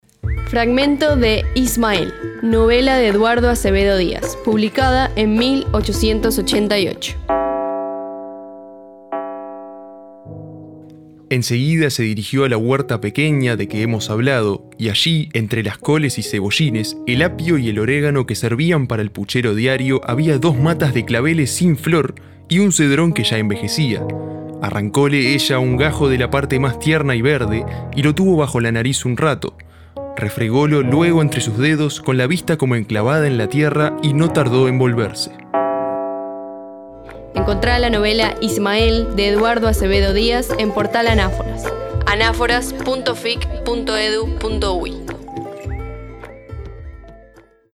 Esta serie recupera fragmentos de obras literarias, entrevistas, citas y conferencias; a través de textos y narraciones con las voces de poetas, periodistas y académicos que integran el repositorio.